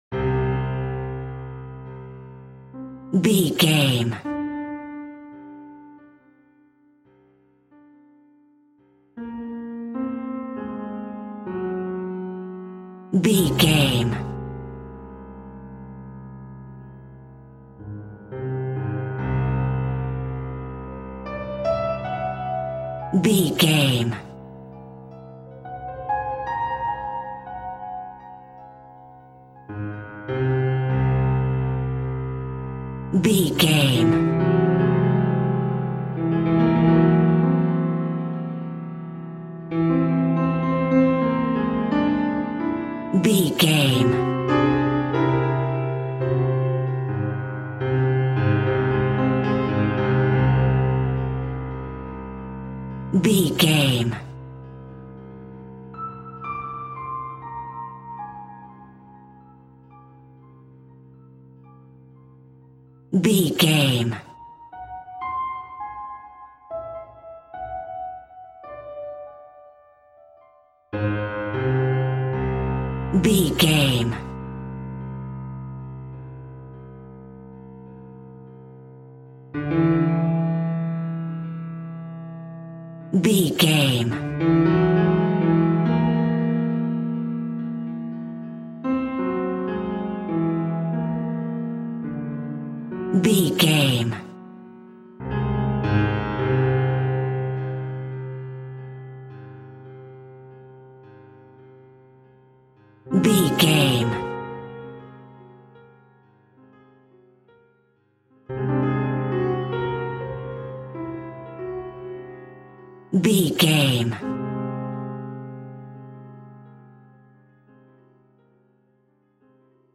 Augmented
Slow
ominous
dark
suspense
haunting
eerie
piano
synth
ambience
pads